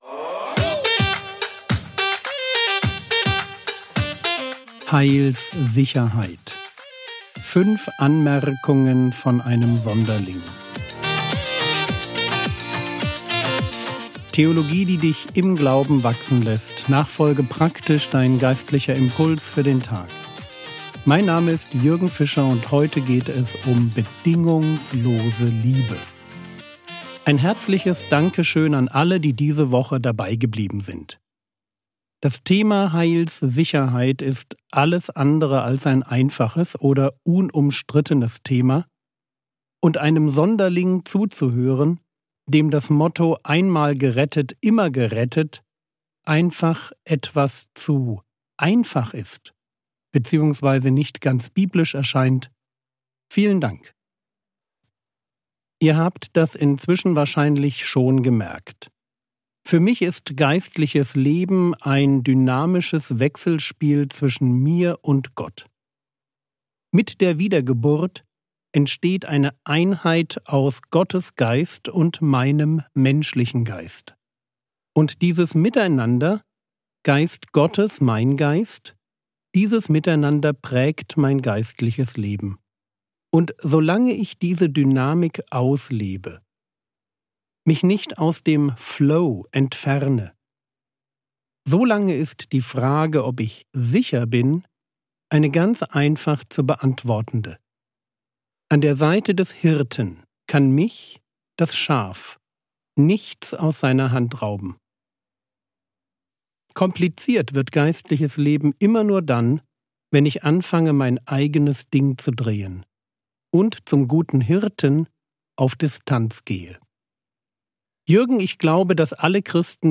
Heilssicherheit (5/5) ~ Frogwords Mini-Predigt Podcast